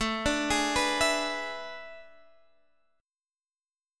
E7/A Chord